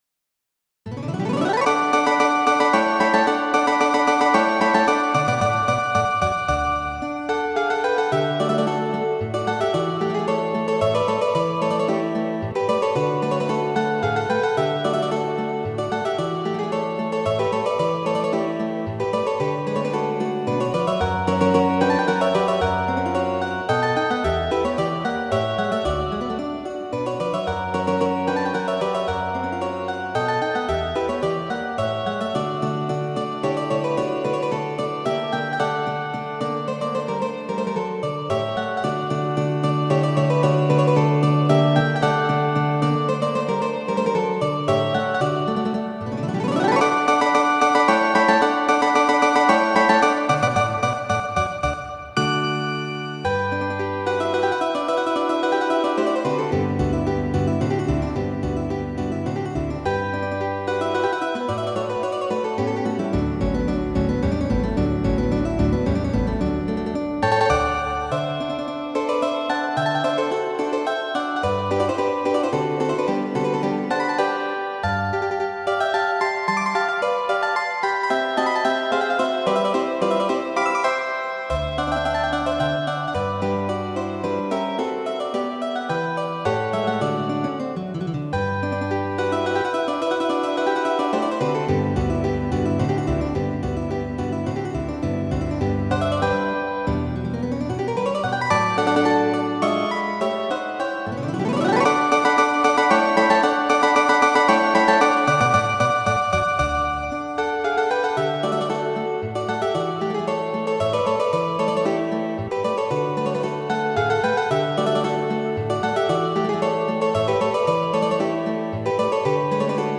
Prelego 2-a de la 3-a Kongreso de HALE (1-a virtuala).